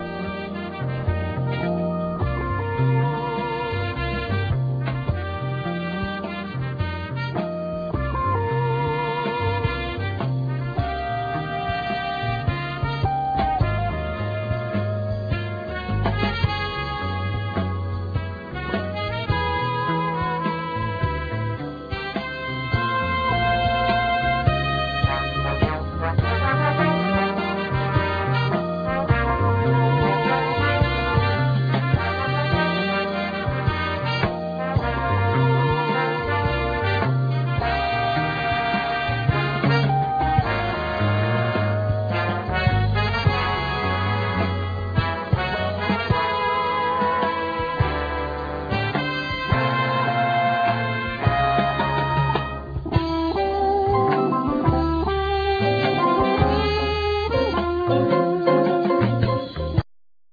Alto+Soprano sax
Piano,Keyboards
Guitar
Bass
Trumpet
Trombone
Drums
Vocals